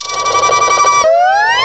cry_not_kricketune.aif